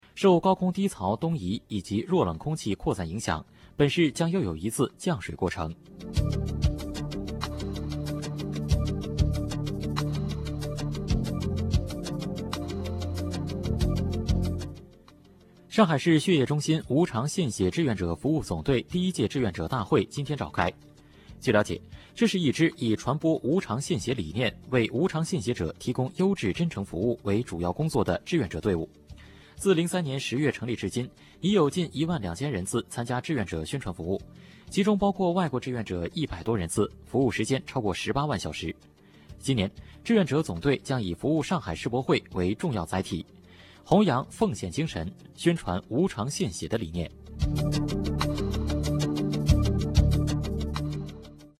东广新闻台报道（中心志愿者服务总队首届大会）